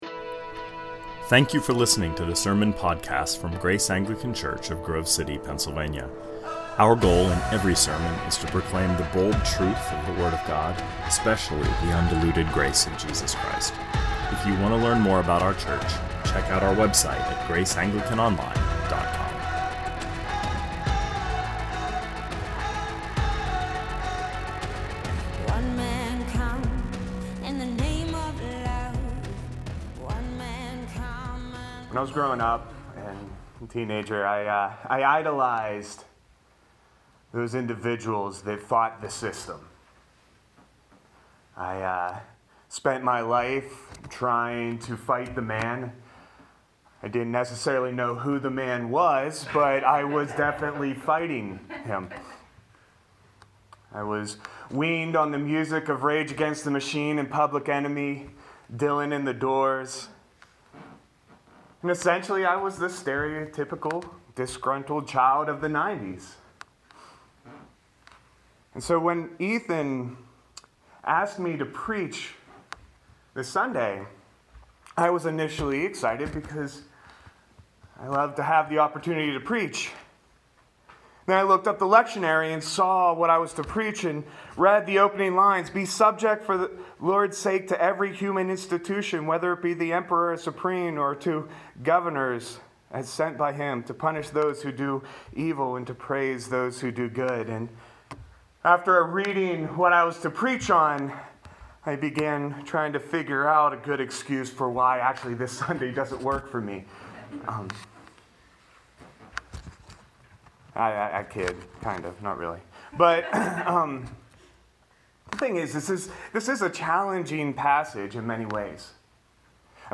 2020 Sermons